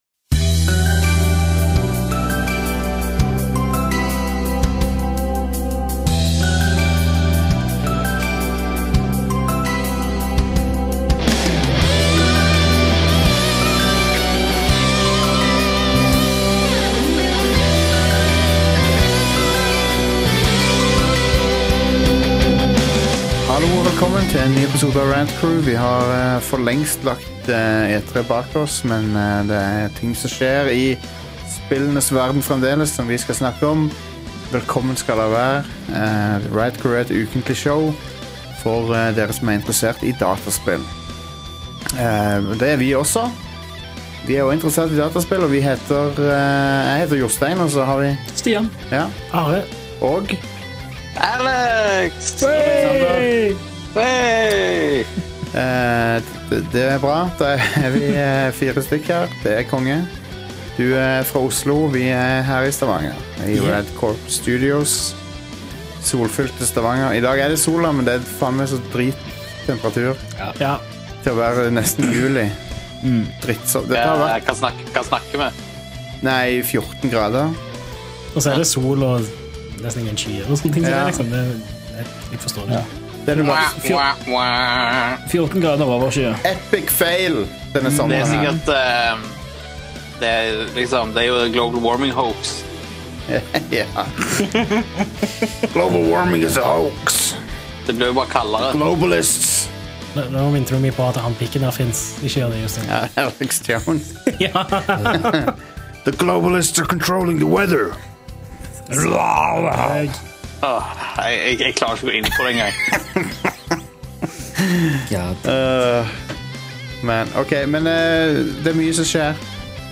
Vi var to brødre i studio denne gangen, og da passer det godt å gjøre en Topp 5-liste som vi har hatt lyst til å gjøre en stund: Topp 5 spillbrødre!
Vi snakker også om «Nex Machina» på PS4 og «Wipeout Omega Collection» i episoden, som begge gir assosiasjoner til en svunnen tid med sitt arkadeinspirerte gameplay. Til slutt snakka vi med lytterne om deres beste SNES-minner.